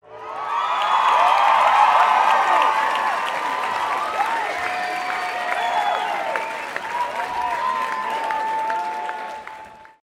oh no no no crowd
crowd no oh-no upset sound effect free sound royalty free Memes